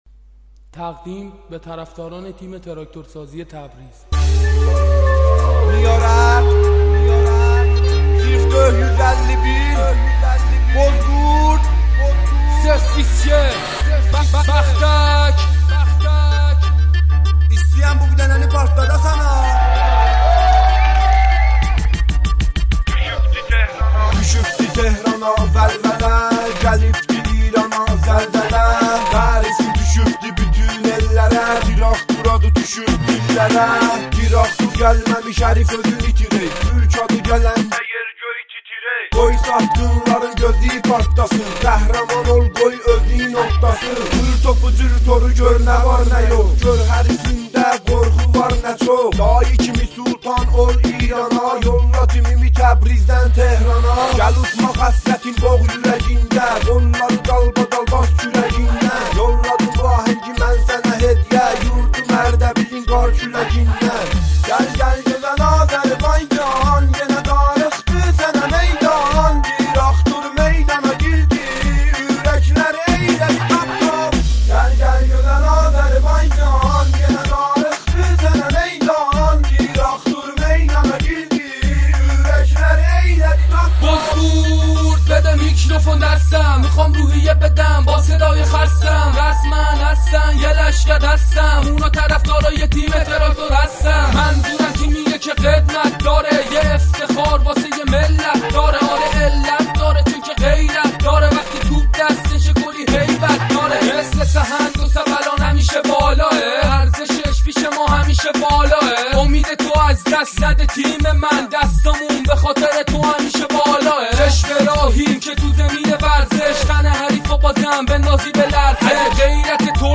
این اهنگ ترکی و فارسی هست از طرف خوانندگان اردبیلی و تهرانی تراکتور امیدوارم خوشتون بیاد